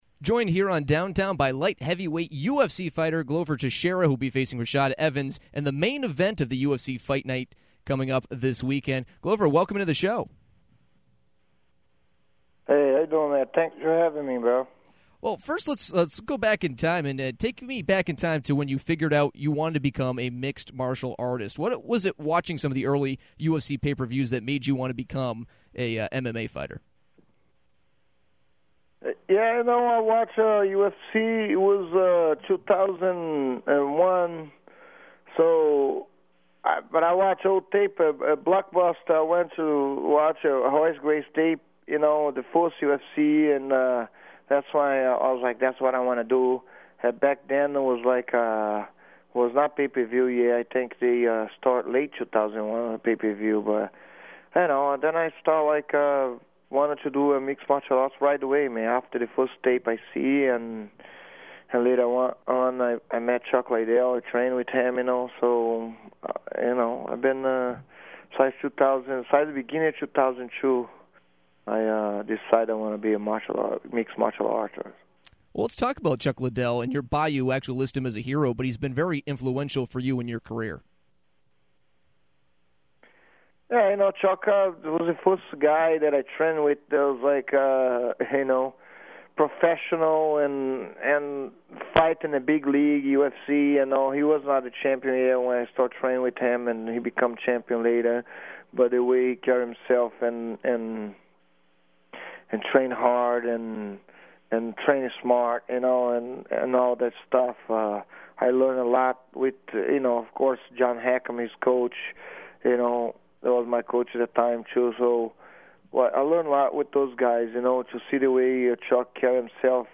UFC fighter Glover Teixeira joined Downtown to talk about his upcoming fight against Rashad Evans this weekend as part of the UFC Fight Night in Tampa. Teixeira spoke about how he got into MMA in the first place and what Chuck Liddell has meant to him and his career. He also spoke on what Evans brings to the table and what he thinks of the light heavyweight division in the UFC.